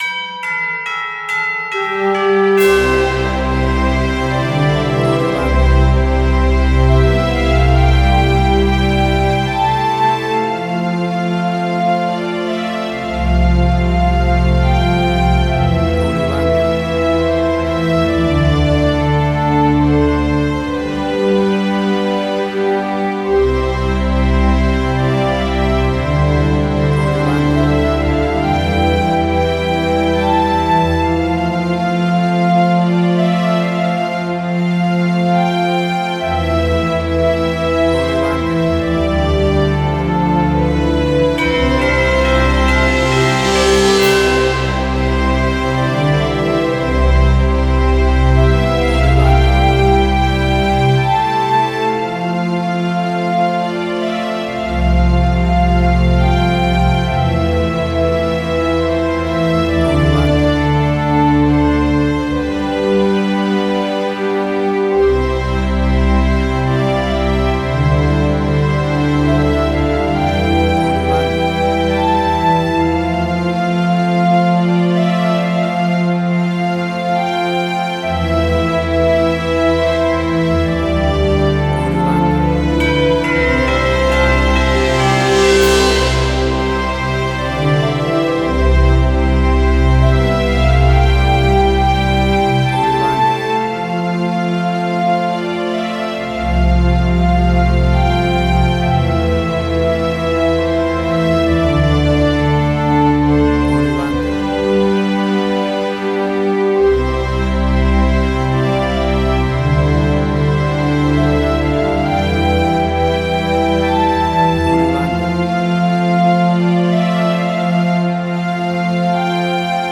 An orchestral version of the classic festive carol
Tempo (BPM): 73